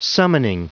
Prononciation du mot summoning en anglais (fichier audio)
Prononciation du mot : summoning